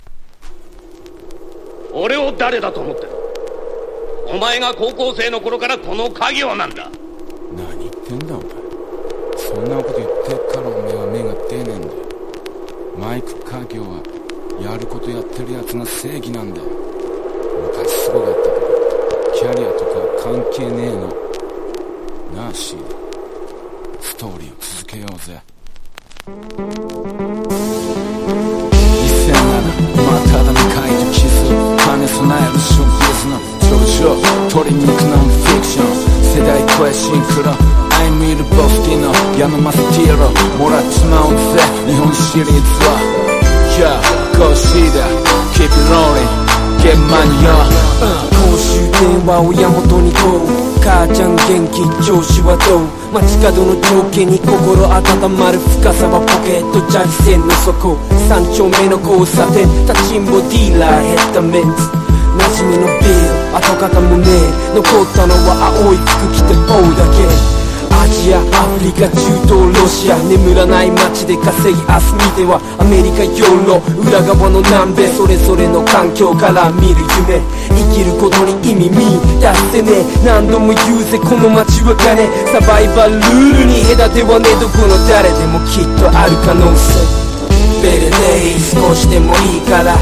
HIPHOP